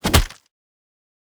Punch Impact (Flesh) 1.wav